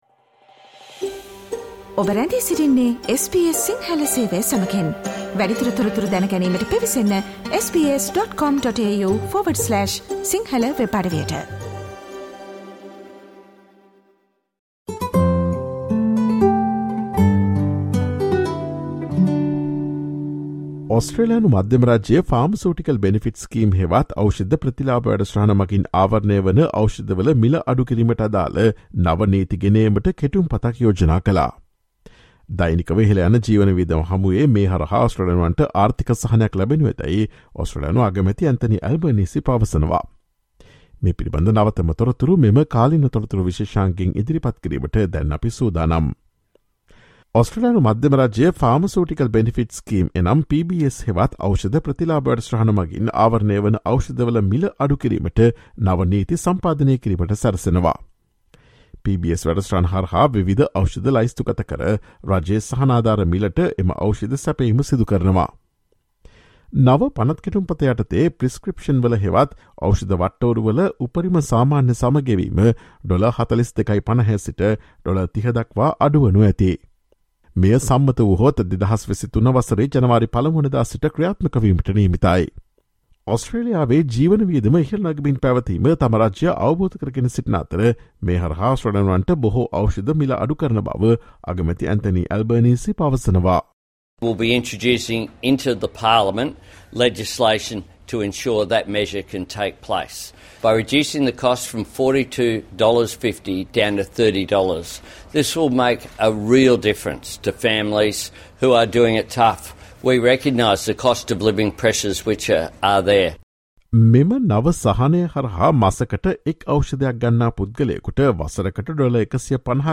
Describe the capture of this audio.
Listen to SBS Sinhala Radio's current affairs feature broadcast on Thursday, 08 September with the latest information on new laws proposed by the Australian federal government to lower co-payments for prescriptions on the Pharmaceutical Benefits Scheme.